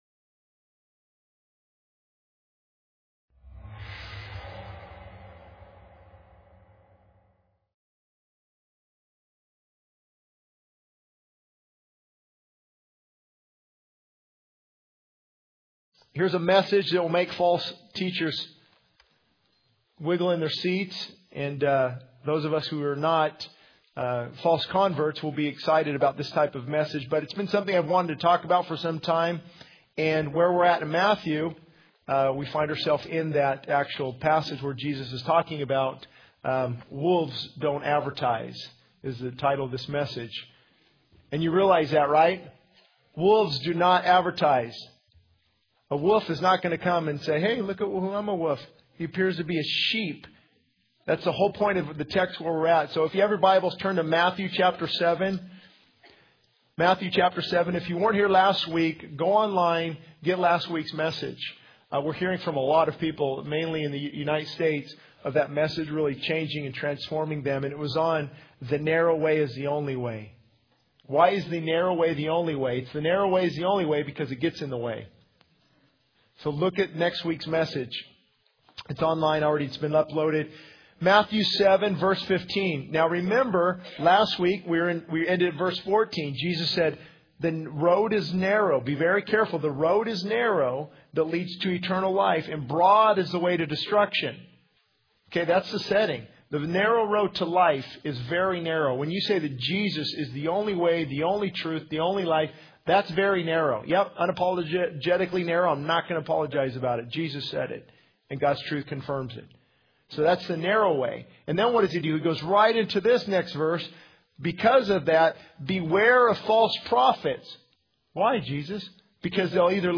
This sermon emphasizes the danger of false teachers who disguise themselves as sheep but lead people astray, focusing on the importance of discerning true from false teachings. It highlights the need to be vigilant, discerning, and anchored in the truth of God's Word to avoid deception and destruction. The message calls for a deep examination of the fruits produced by teachings and urges listeners to seek the truth that leads to salvation and transformation.